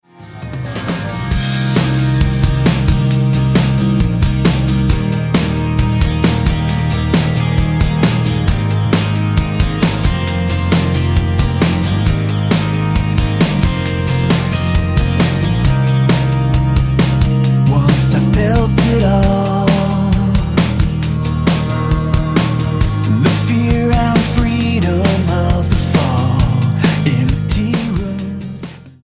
STYLE: Pop
Lots of jangling guitars